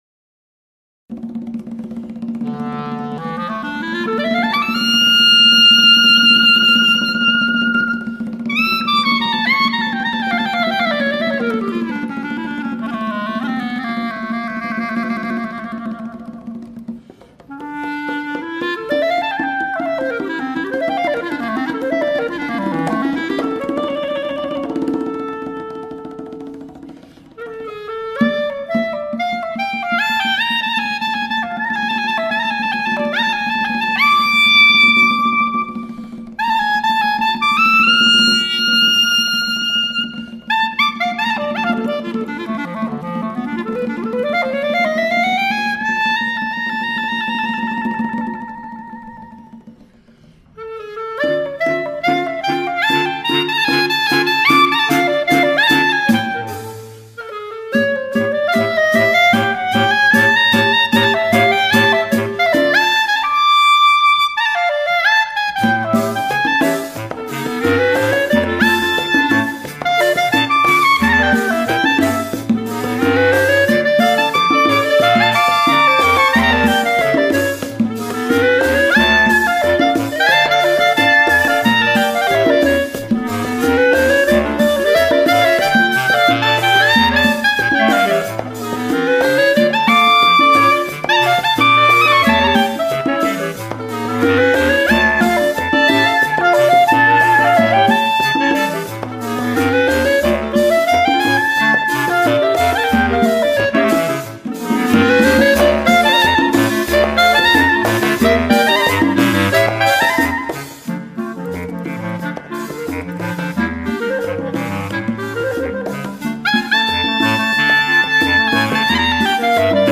Porro